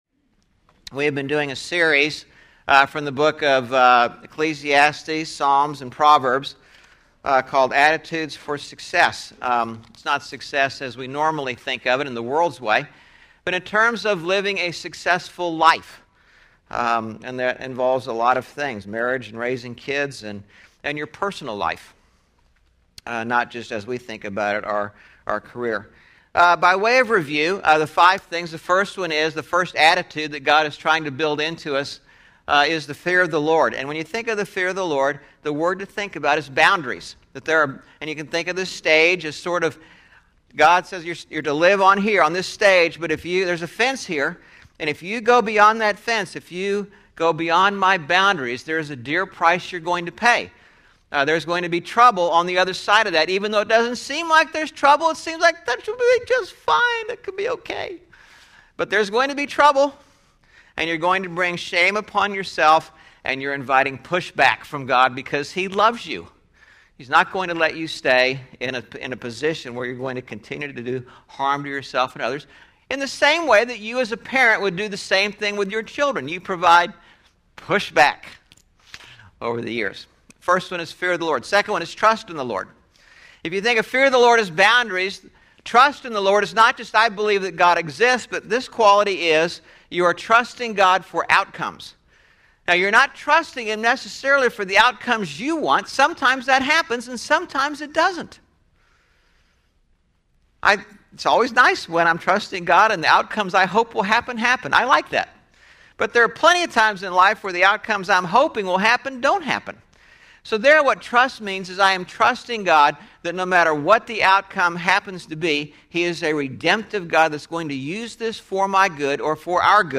11/13/11 Sermon (Attitudes for Success part 6) – Churches in Irvine, CA – Pacific Church of Irvine